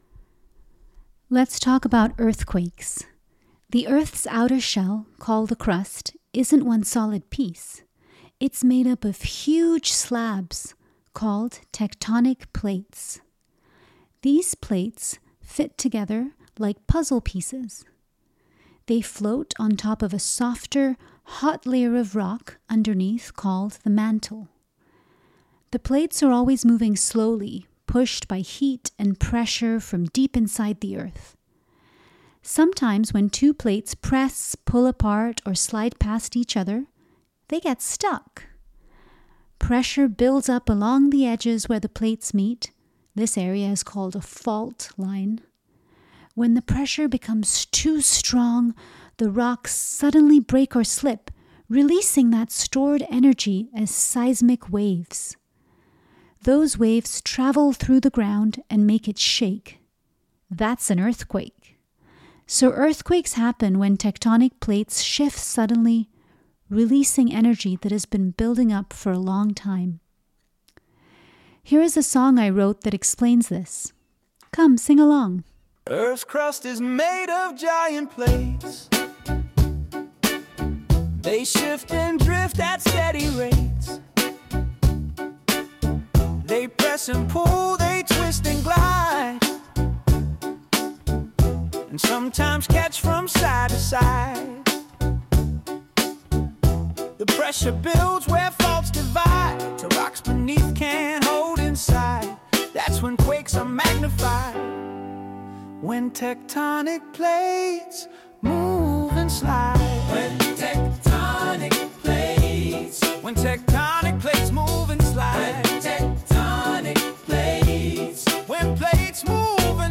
WonderWise is a joyful podcast that makes learning fun for children. Each episode begins with a simple narration explaining the science behind a big question, followed by a catchy educational song.
The lyrics in every episode are written by me, and the music and vocals are generated with the help of Artificial Intelligence software.